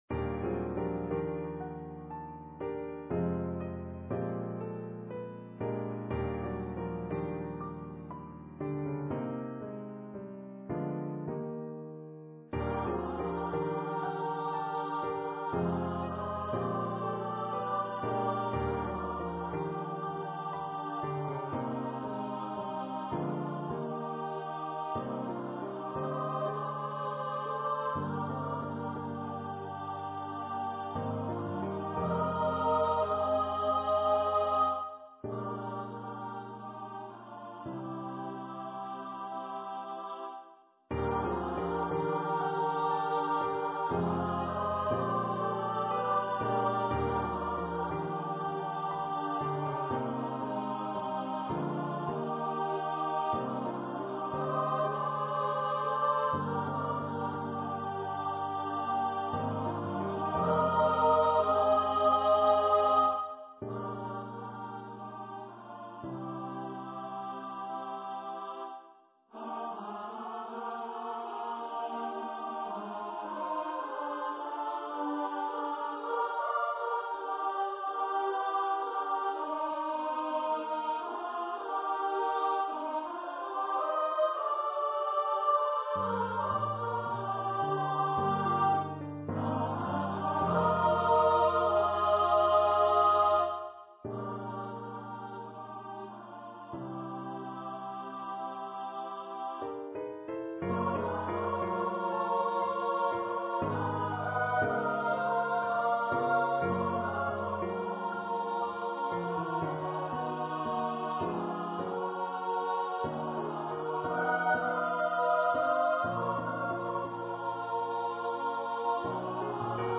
for SSA choir
for choir and orchestra or piano
Choir - 3 part upper voices